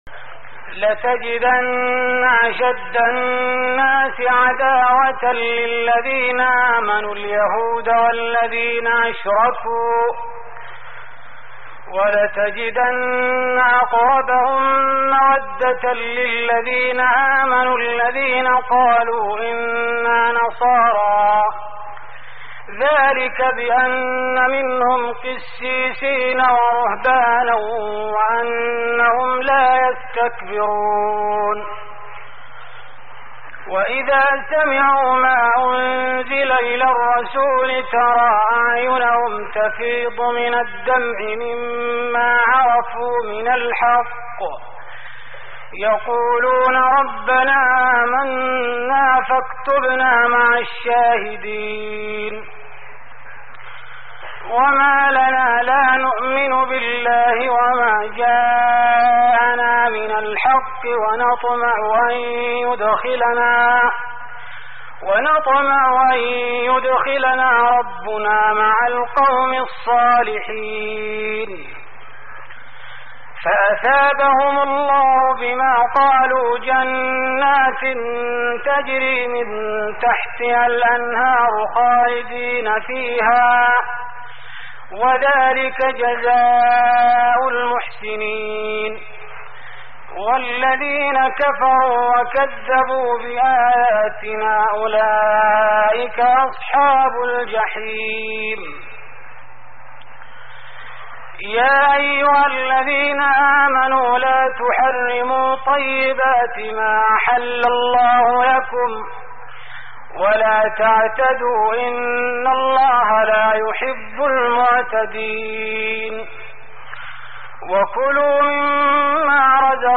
تهجد رمضان 1416هـ من سورة المائدة (82-120) و الأنعام (1-35) Tahajjud Ramadan 1416H from Surah AlMa'idah and Al-An’aam > تراويح الحرم النبوي عام 1416 🕌 > التراويح - تلاوات الحرمين